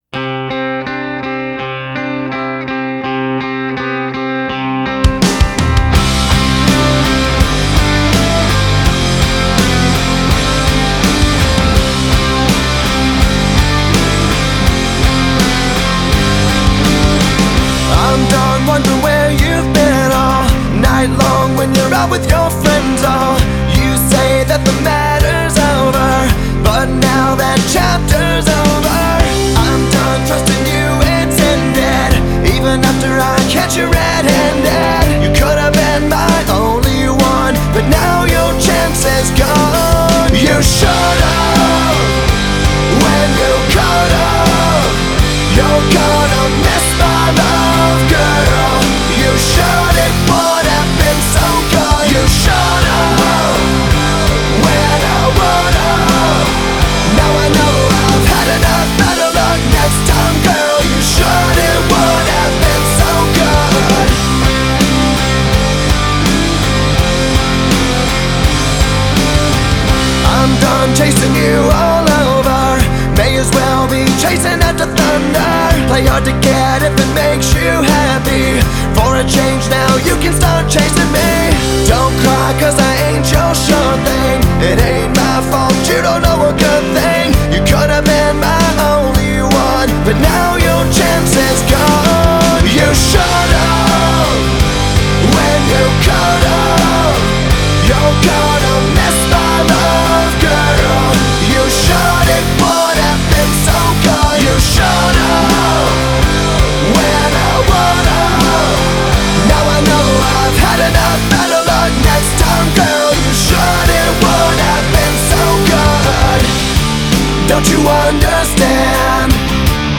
Hard Rock
rock music